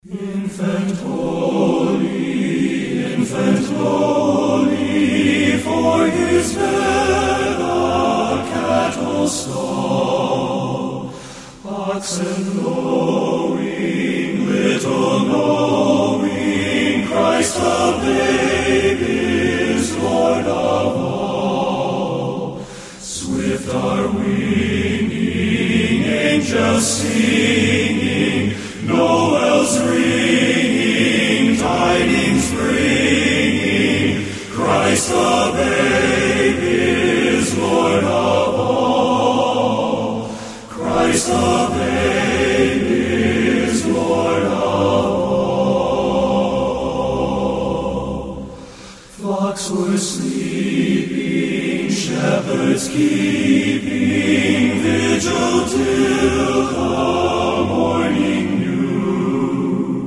Performers: SMS Men's Chorus